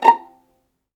VIOLINP .8-R.wav